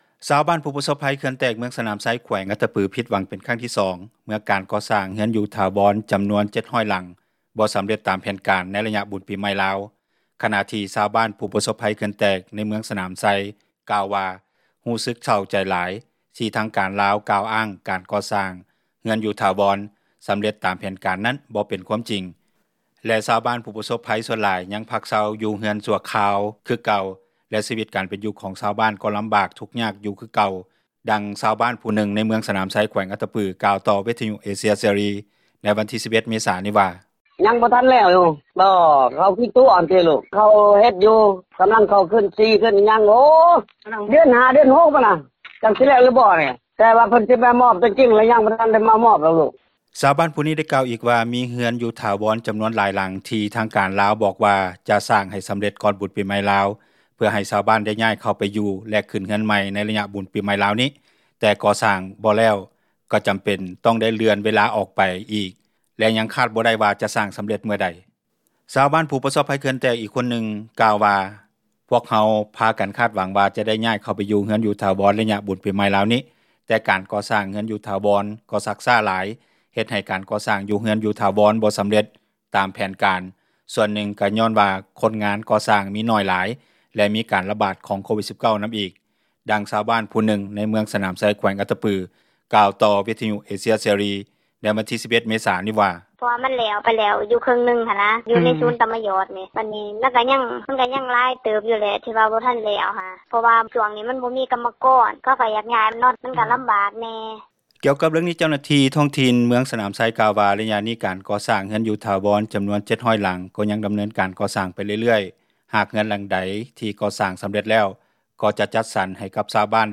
ດັ່ງຊາວບ້ານຜູ້ນຶ່ງໃນ ເມືອງສນາມໄຊ ແຂວງອັດຕະປື ກ່າວຕໍ່ວິທຍຸເອເຊັຽເສຣີ ໃນວັນທີ 11 ເມສາ ນີ້ວ່າ:
ດັ່ງເຈົ້າໜ້າທີ່ ທ້ອງຖິ່ນເມືອງສນາມໄຊ ແຂວງອັດຕະປື ທ່ານນຶ່ງ ກ່າວຕໍ່ວິທຍຸເອເຊັຽເສຣີ ໃນວັນທີ 11 ເມສານີ້ວ່າ: